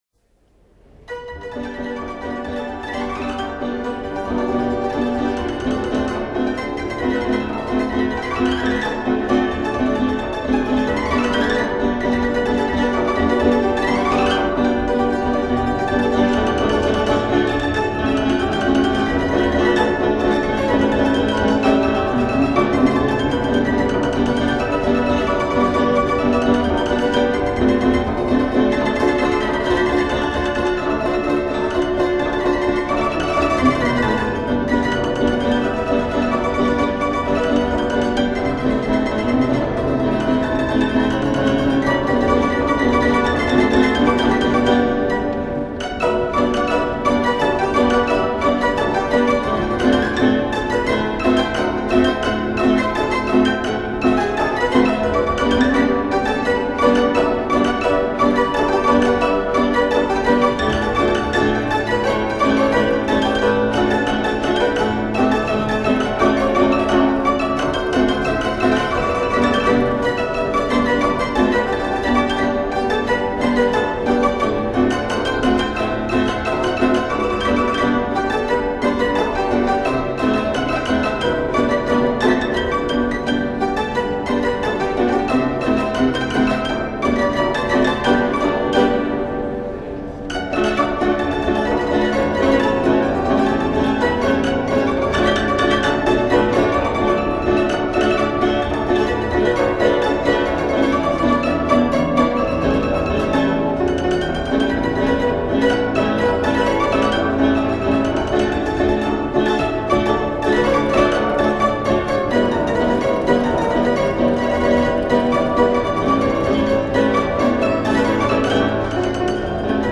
enregistrement d'un piano mécanique du début du XXe siècle
Le cylindre enchaine une valse, une scottiche et une polka
Pièce musicale éditée